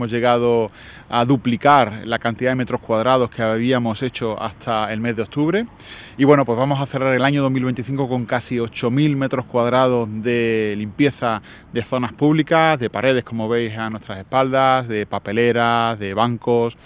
Antonio-Urdiales-concejal-Sostenibilidad-Medioambiental-y-Energetica-limpieza-pintadas-vandalicas.wav